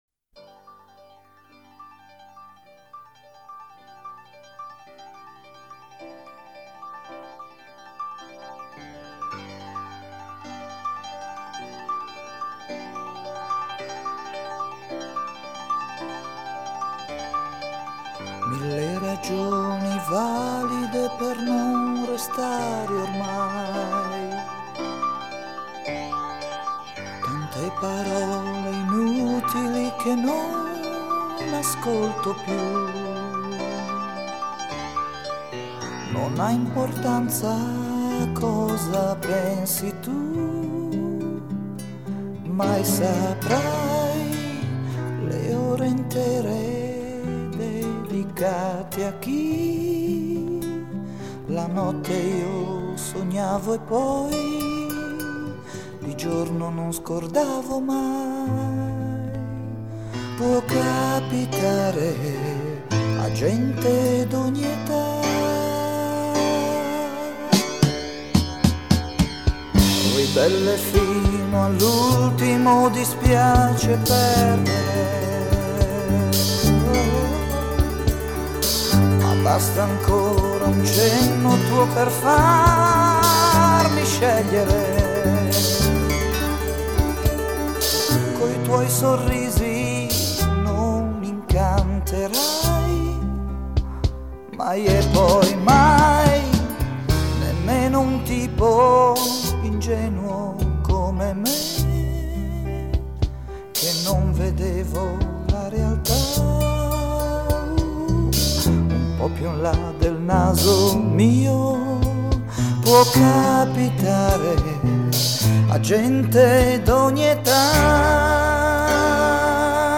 Жанр: Electronic. Synth-pop, Disco